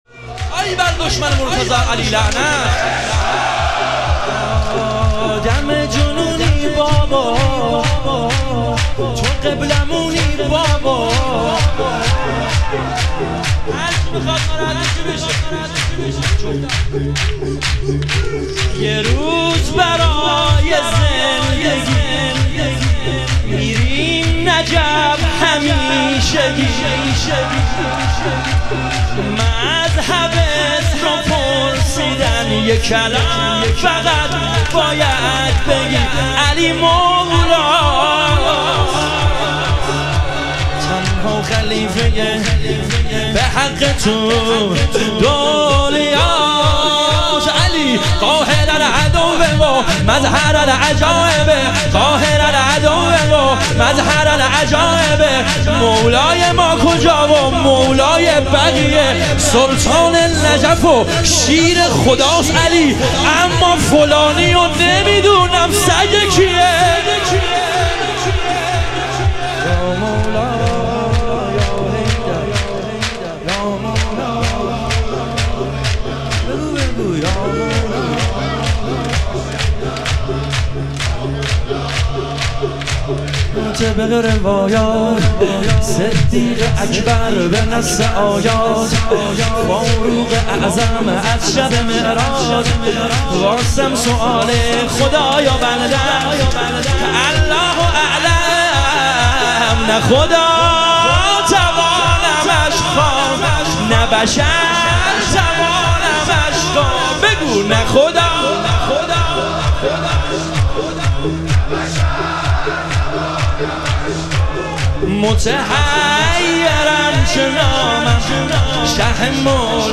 شهادت امام کاظم علیه السلام - شور